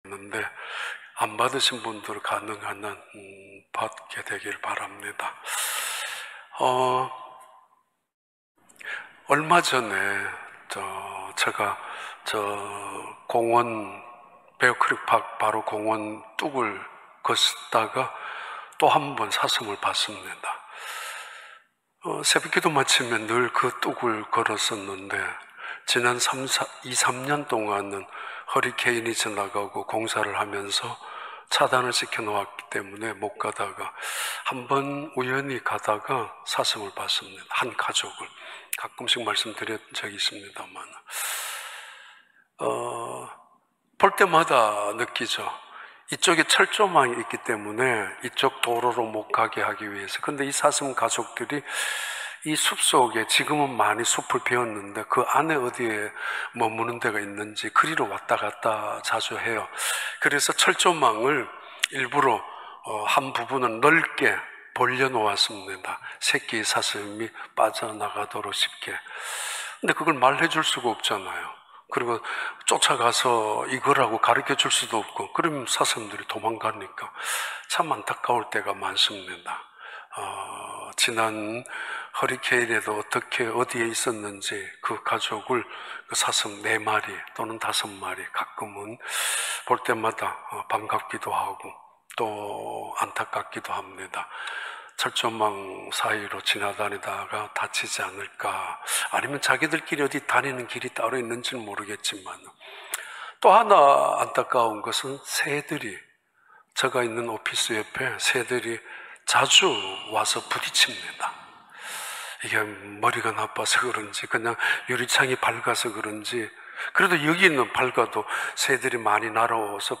2021년 5월 30일 주일 4부 예배